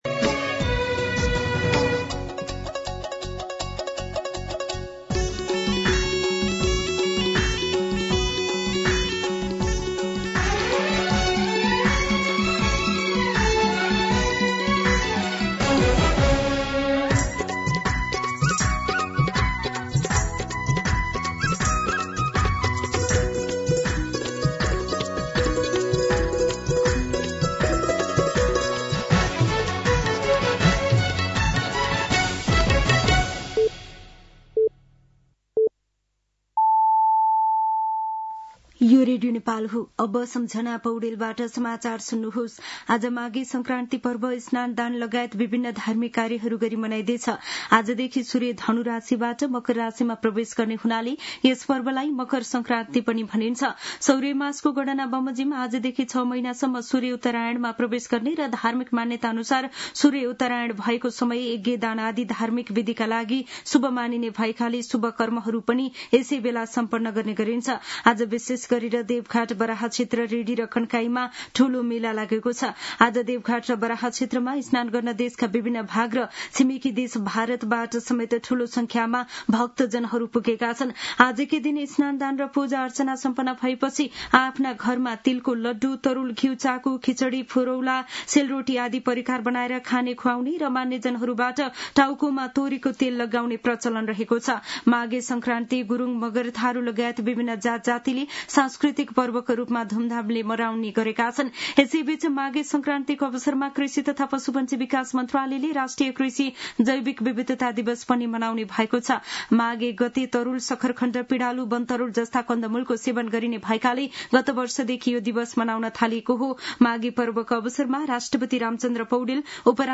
दिउँसो ४ बजेको नेपाली समाचार : १ माघ , २०८२